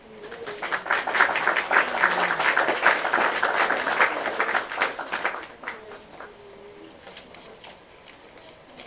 拍手
描述：养老院里一小部分人鼓掌表演的简短录音。
标签： 掌声 鼓掌 音乐会 疗养院 小室
声道立体声